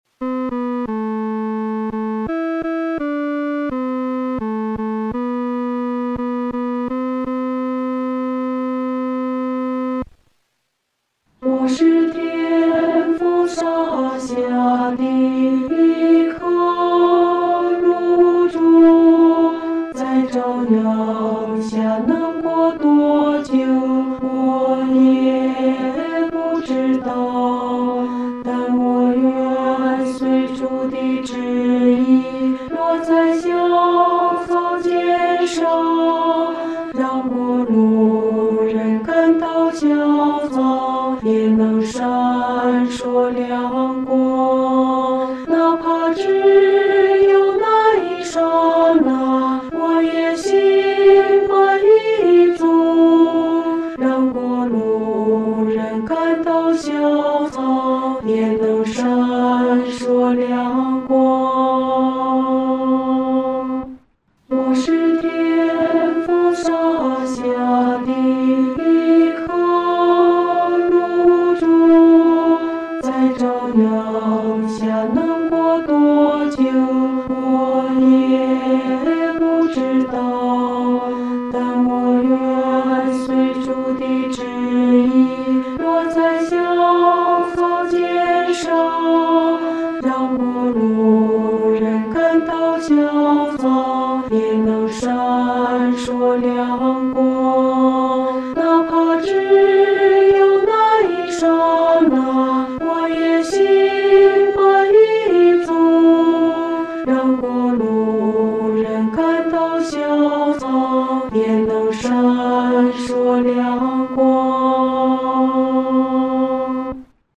合唱
女低
曲调欢快、活泼，好唱易记，既适合成人唱，又适合年轻人唱，还特别适合儿童表演唱。